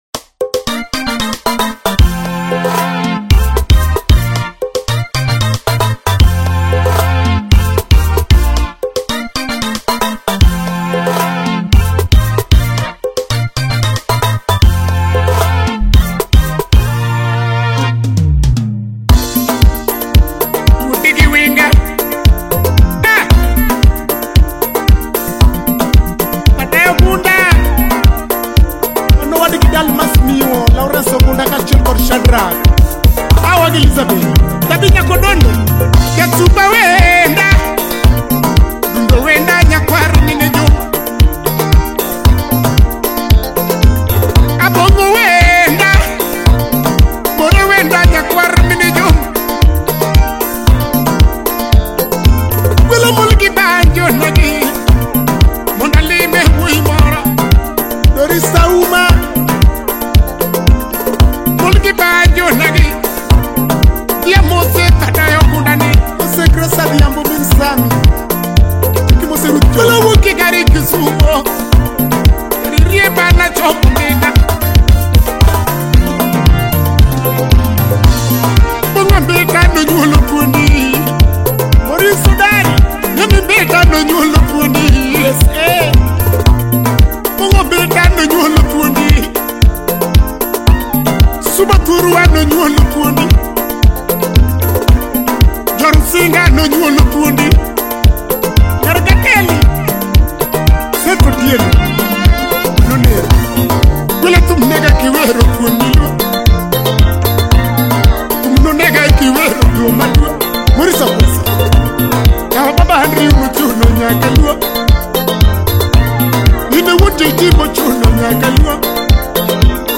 Genre: Afro-Beats